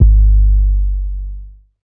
808 [Wonderful].wav